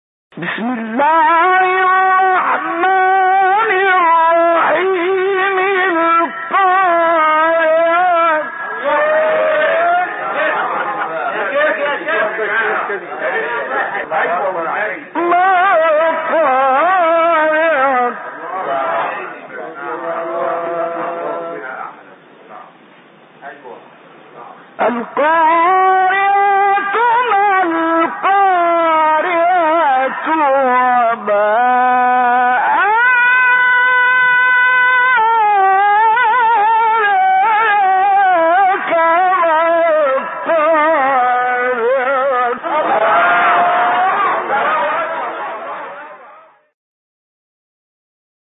استاد «محمد عبدالعزیز حصان» یکی از مشاهیر تلاوت قرآن کریم است و به لحاظ برجستگی در ادای نغمات و دقت در ظرافت‌های وقف و ابتداء او را «ملک الوقف و الإبتدا و التنغیم» یعنی استاد الوقف و الابتداء و تلوین النغمی لقب داده‌اند.
در ادامه ۵ قطعه کوتاه از زیباترین تلاوت‌های استاد محمد عبدالعزیز حصان ارائه می‌شود.